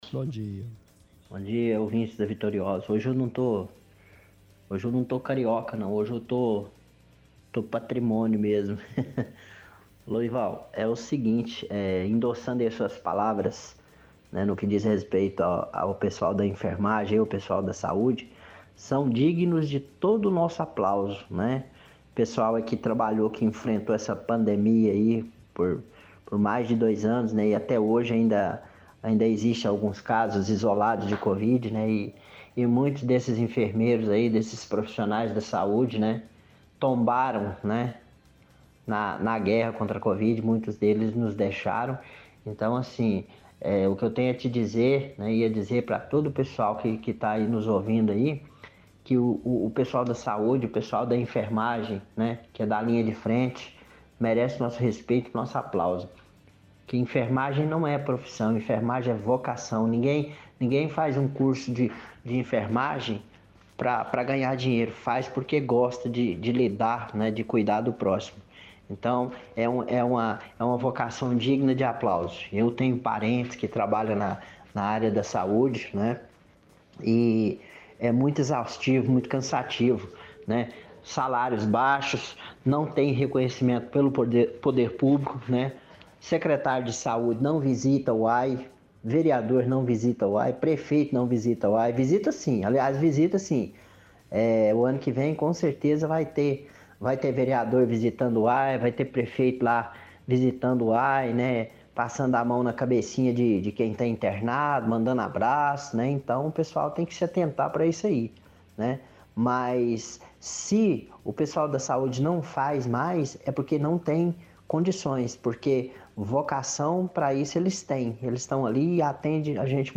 – Ouvinte congratula profissionais da área da saúde pelo dia da enfermagem.